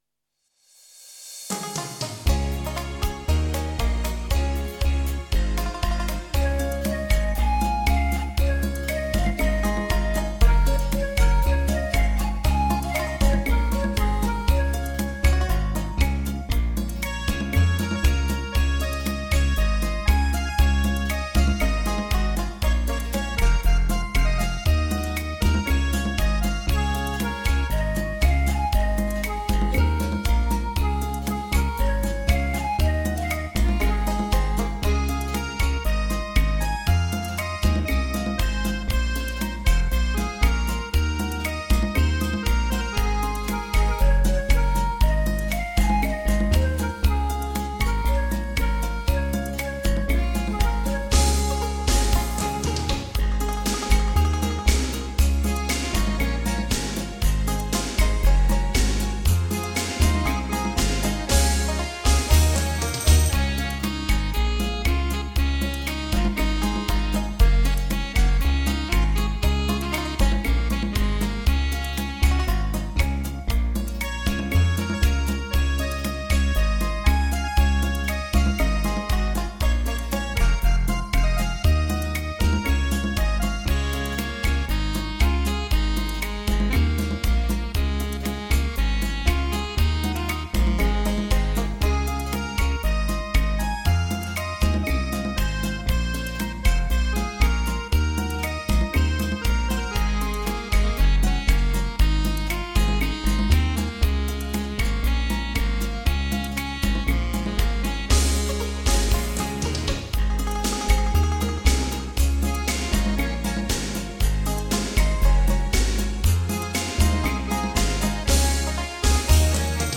当代舞曲大全
越剧 伦巴